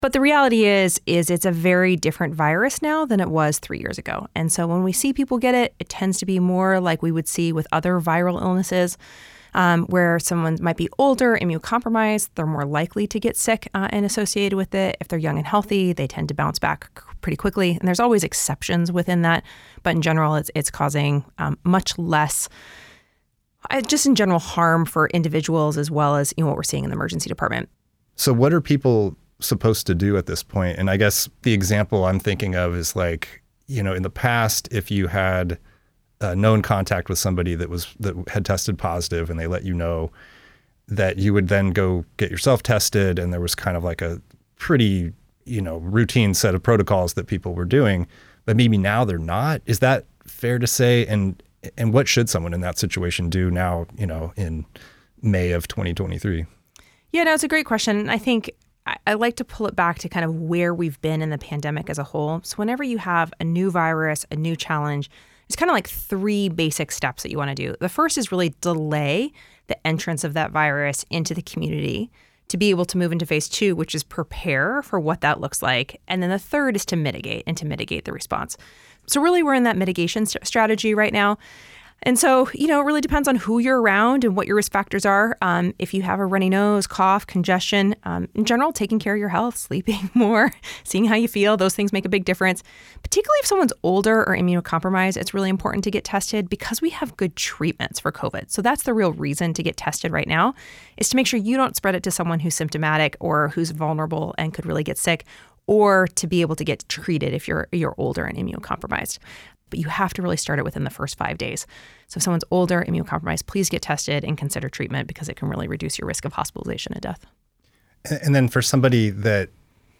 Dr. Anne Zink at Alaska Public Media on Tuesday.